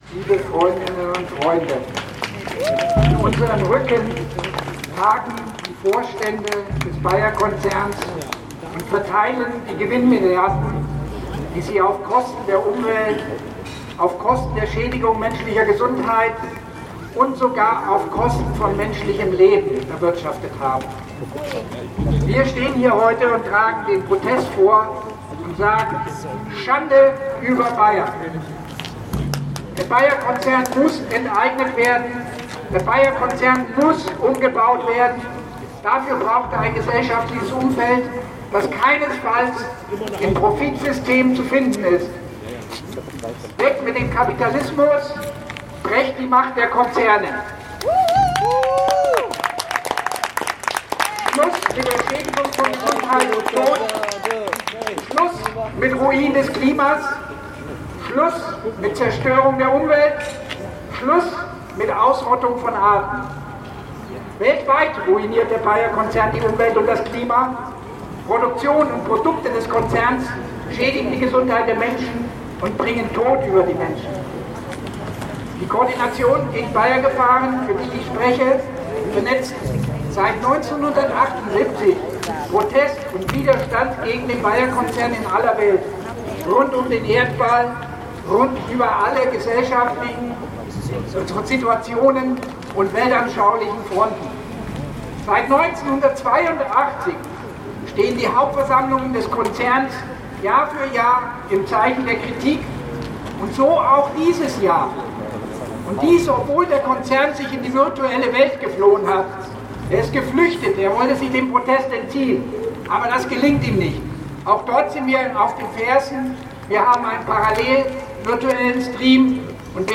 „Fridays for Future Leverkusen“ und „Coordination gegen BAYER-Gefahren“: Demonstration und Kundgebung gegen die „BAYER AG“ Hauptversammlung 2022 (Audio 7/10)